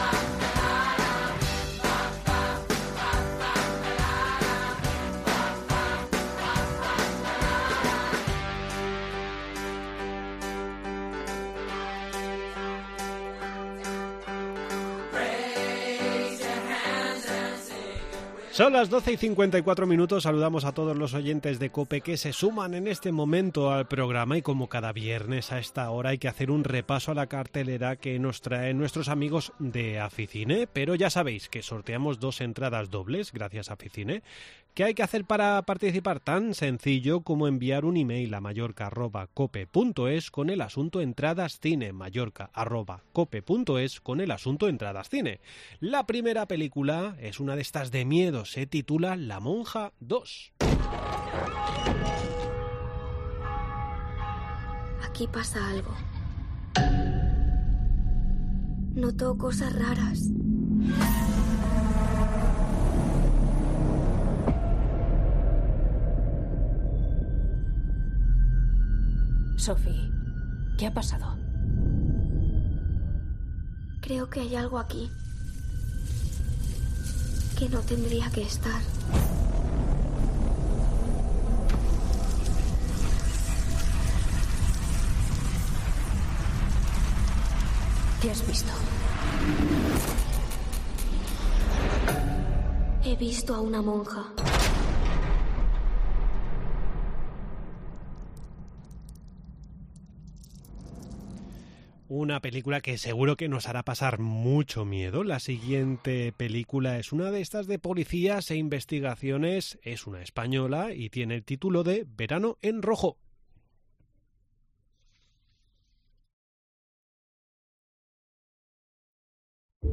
Entrevista en La Mañana en COPE Más Mallorca, viernes 08 septiembre de 2023.